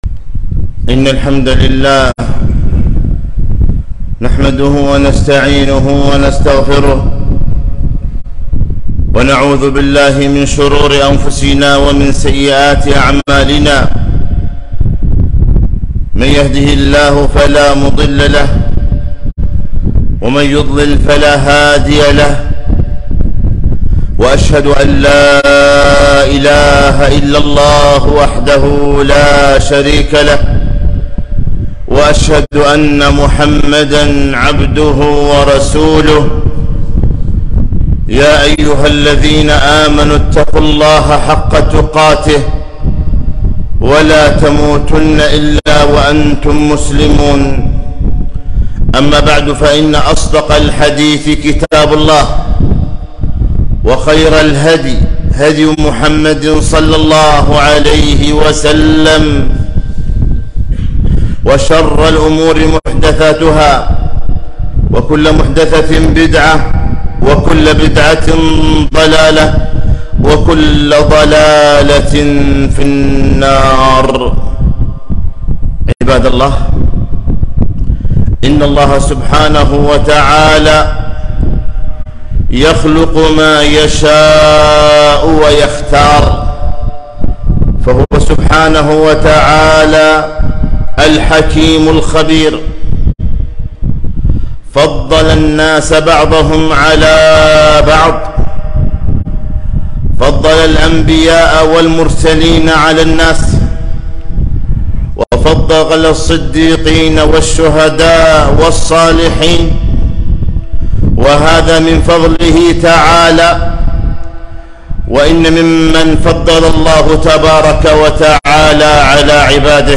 خطبة - (فضل الصحابة رضي الله عنهم ومكانتهم)